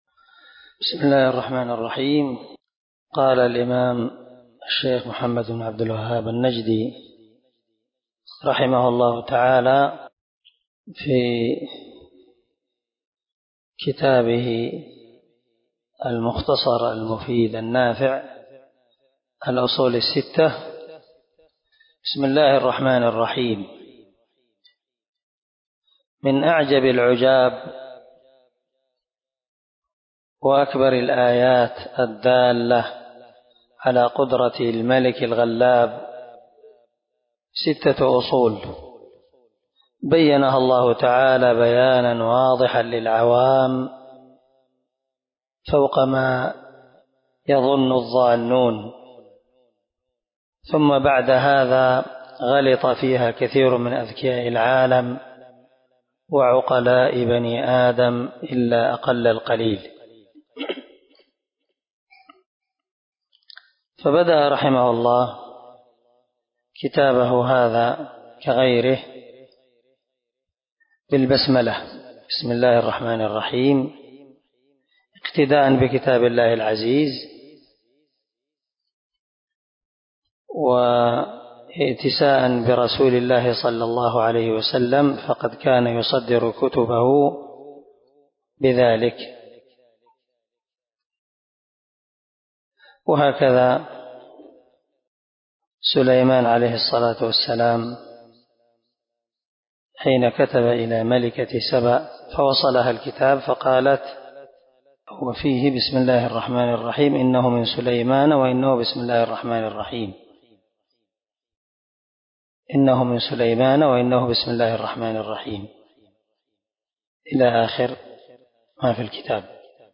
🔊 الدرس 1 من شرح الأصول الستة (المقدمة مع الأصل الأول )